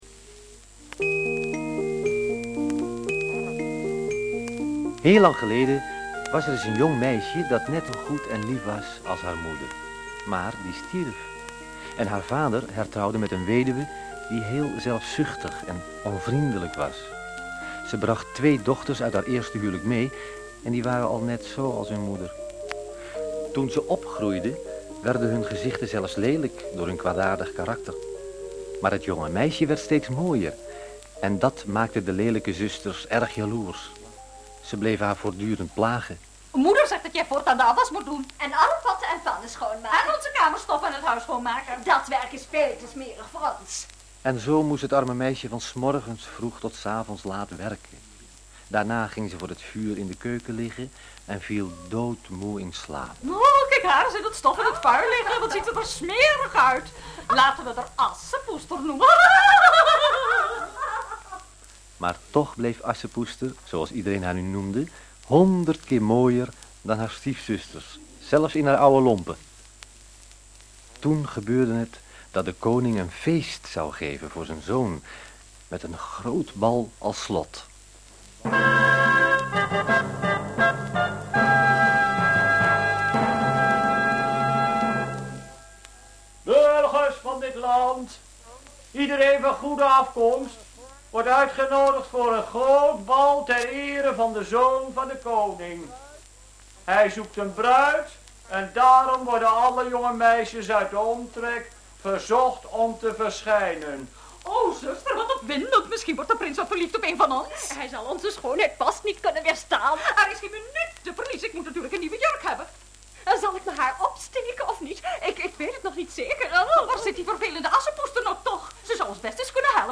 Hoorspel
onbekende acteurs
Serie grammofoonplaatjes die (bij aankoop) werden geleverd met een speciale envelop, zodat die als wenskaart verstuurd konden worden.